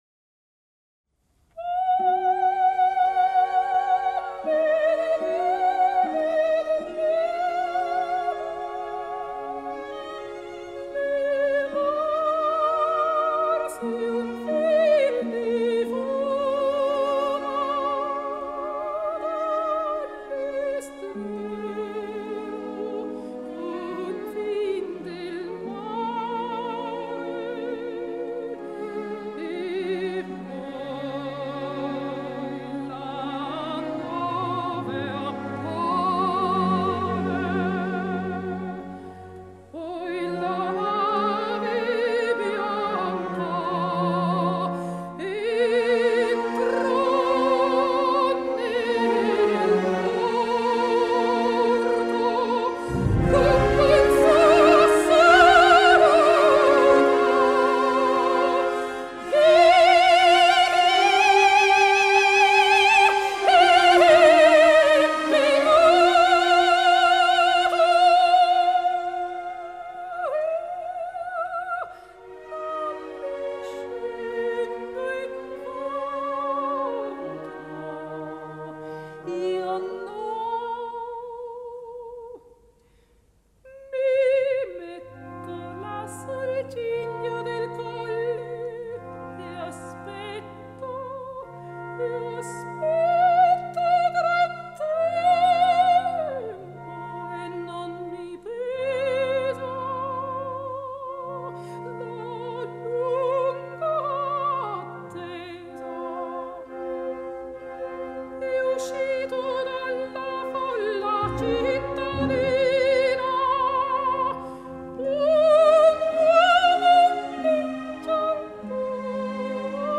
音樂類型：古典音樂
首度演出不朽歌劇二重唱！